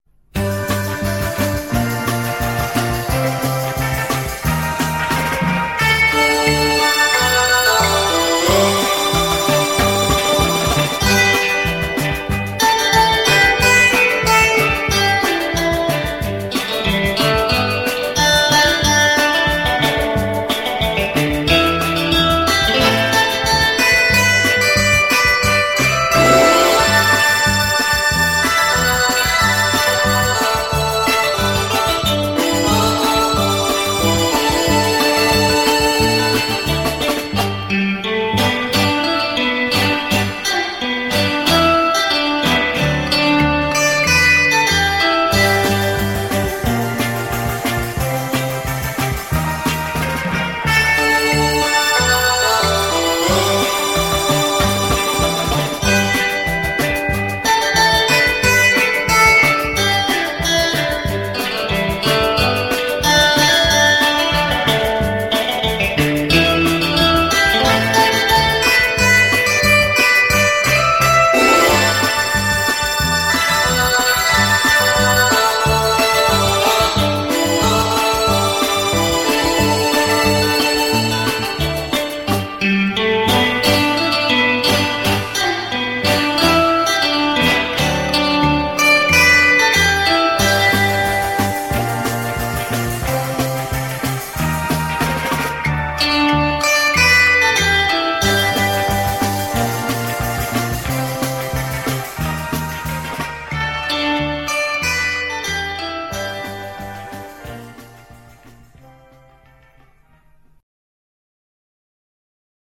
GUITAR MUSIC